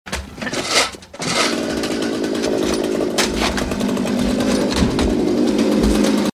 SMALL MOTOR SPUTTERNING.mp3
A small boat engine sputtering, while trying to sail a small lake.
small_motor_sputterning_n3y.ogg